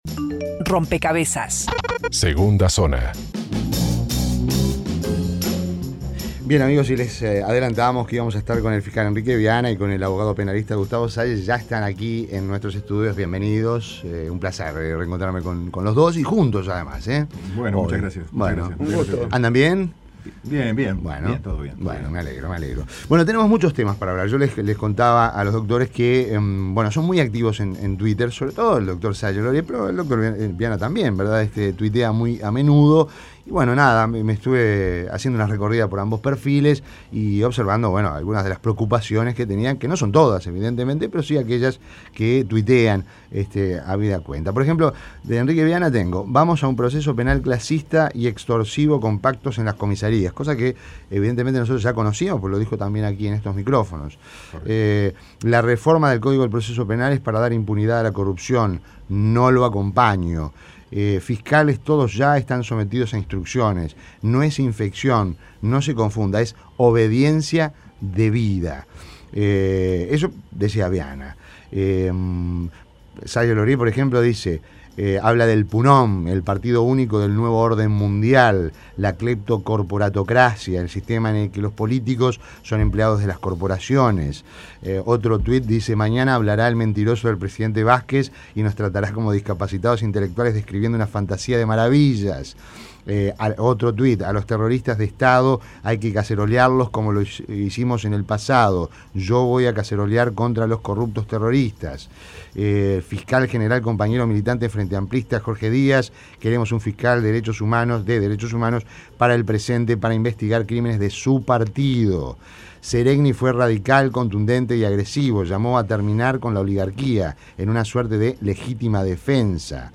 Enetrevista en Rompkbzas